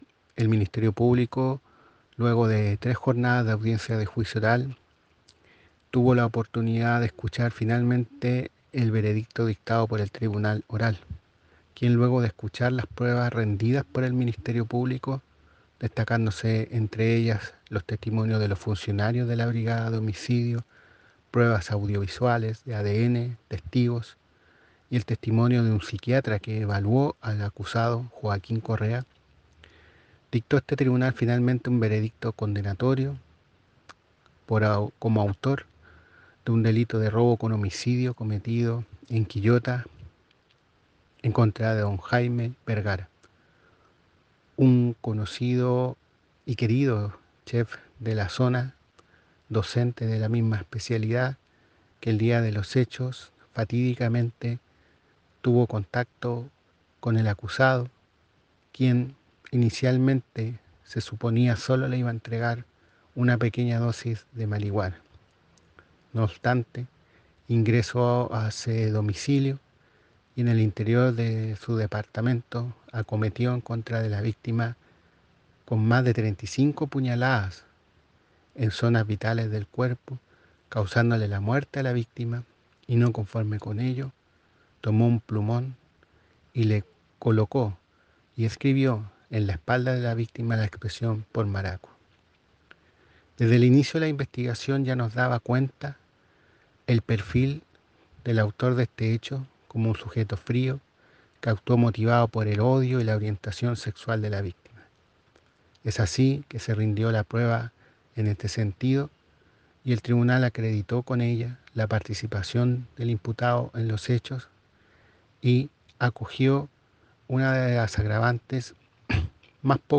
Así lo informó el fiscal jefe de Quillota, César Astudillo:
fiscal-jefe-de-Quillota-Cesar-Astudillo.mp3